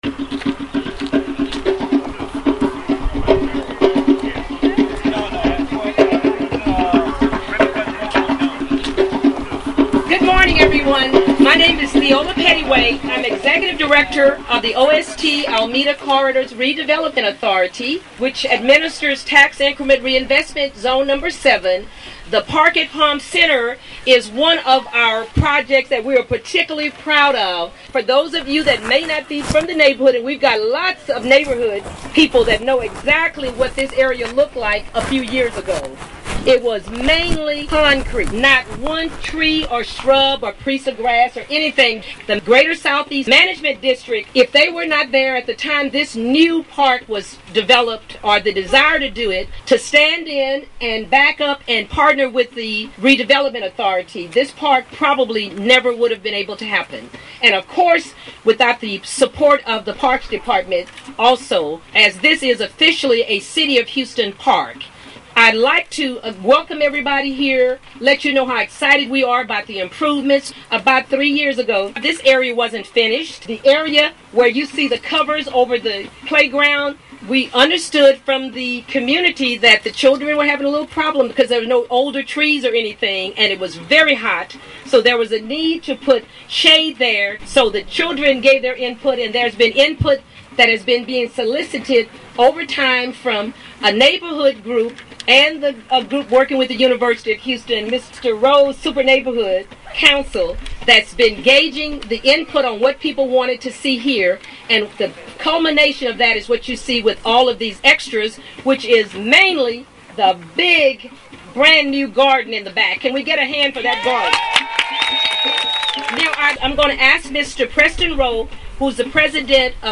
As an archive the events on April 20, 2013, this mixtape includes clips from the AWE Celebration at the Grand Re-Opening and Ribbon Cutting of the Park at Palm Center at 5300 Griggs.
Community Garden planting
YDM gospel choir